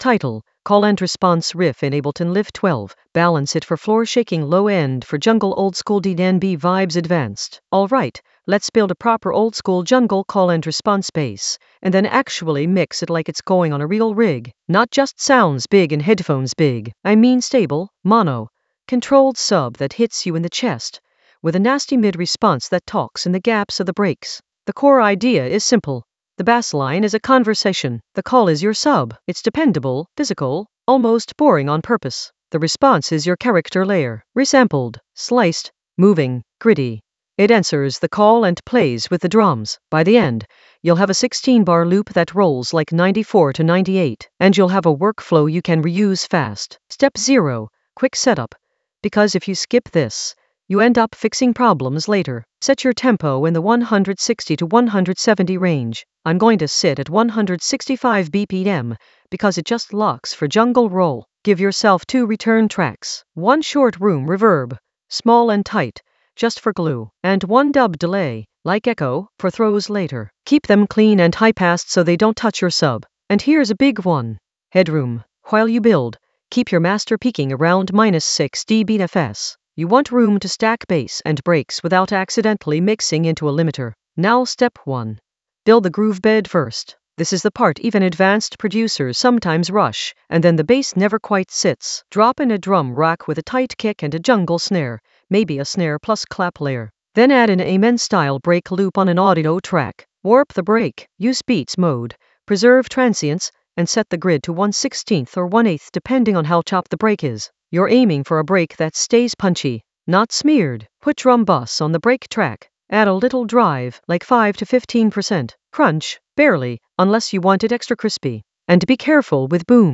Narrated lesson audio
The voice track includes the tutorial plus extra teacher commentary.
An AI-generated advanced Ableton lesson focused on Call-and-response riff in Ableton Live 12: balance it for floor-shaking low end for jungle oldskool DnB vibes in the Sampling area of drum and bass production.